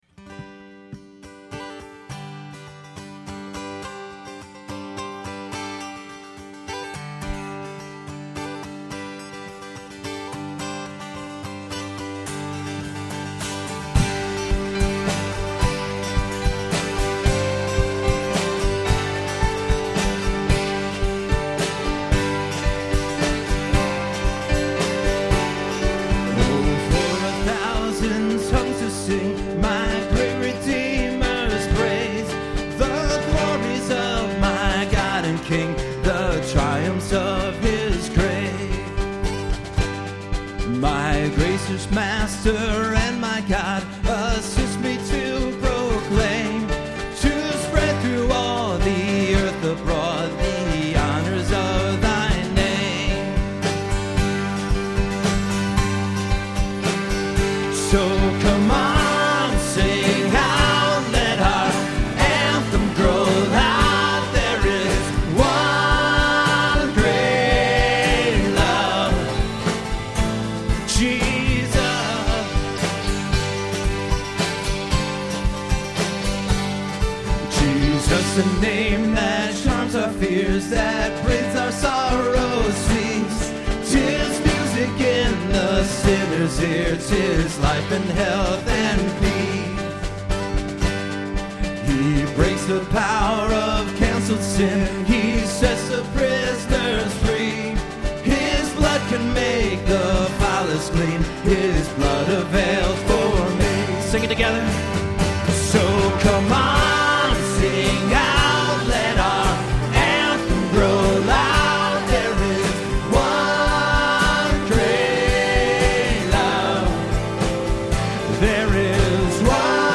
Performed live at Terra Nova - Troy on 4/12/09 (Easter).